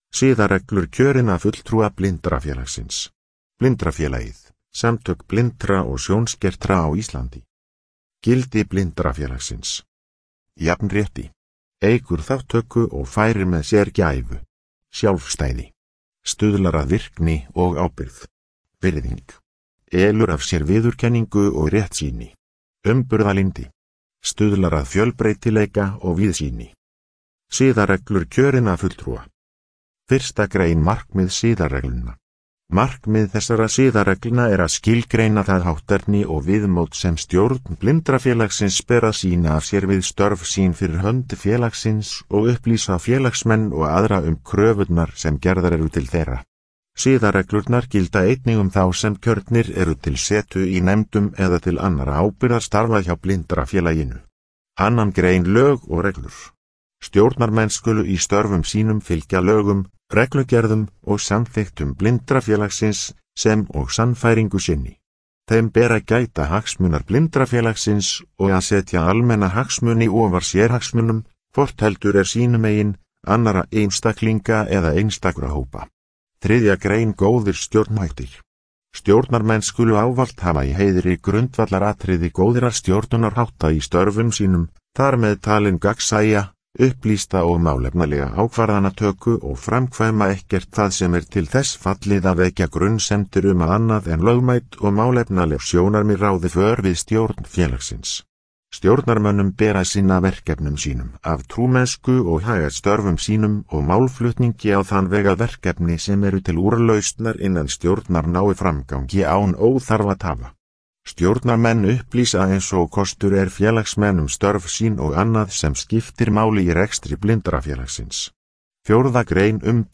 Upplesið